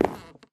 Sound / Minecraft / step / wood6